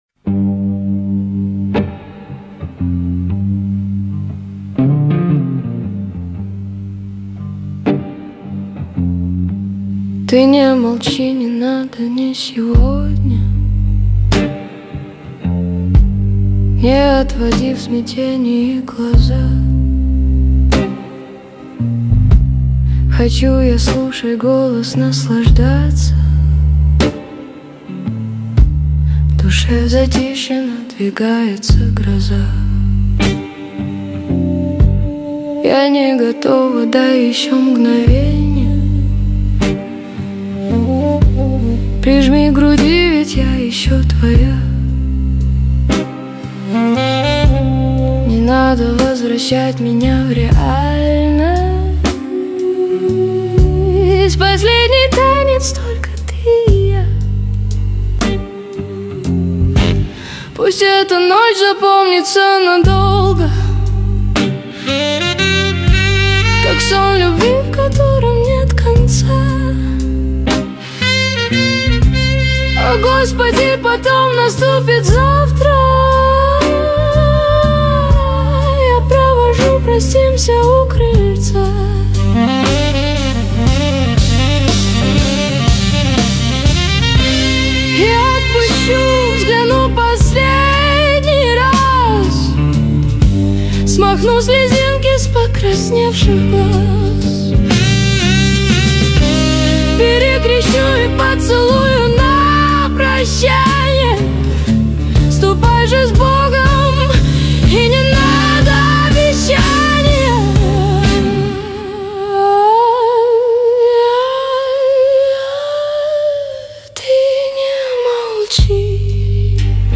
13 декабрь 2025 Русская AI музыка 73 прослушиваний